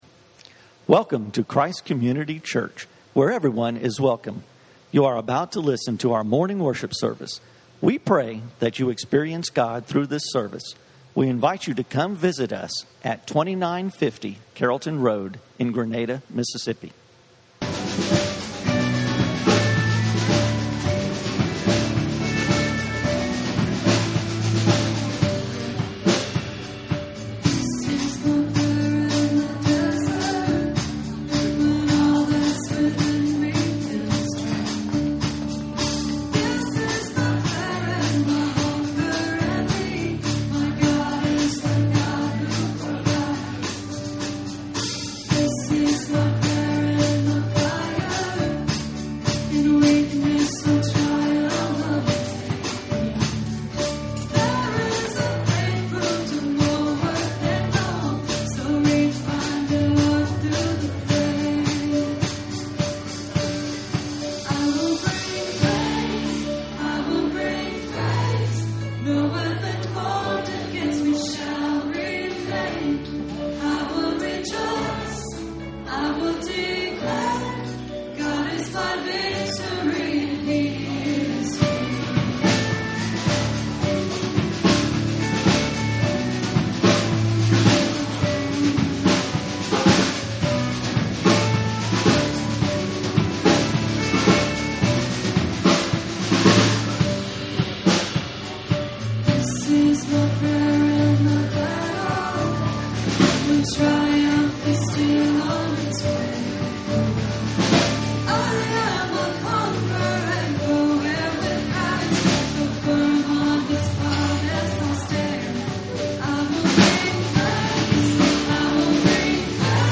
Christ Community On Mission Jonah - Messages from Christ Community Church.